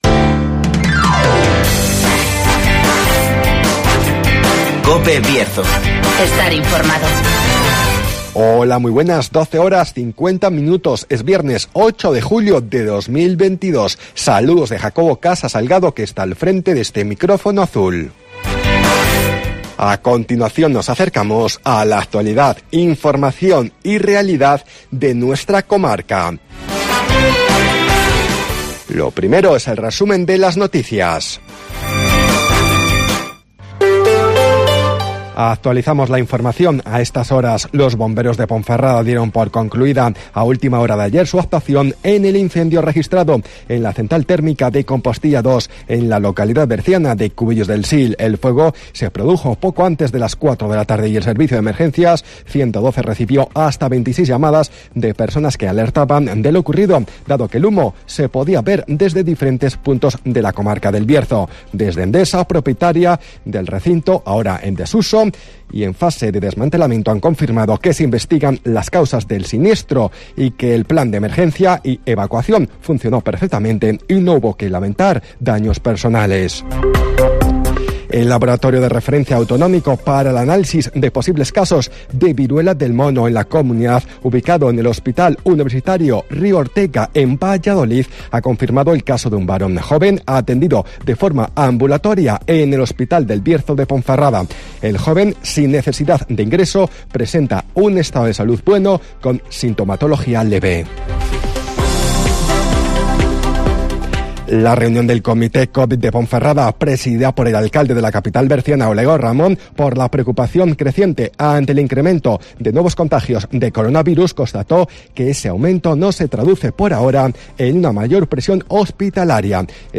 Resumen de las noticas, El Tiempo y Agenda